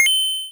pickup.wav